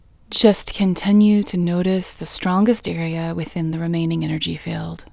IN Technique First Way – Female English 16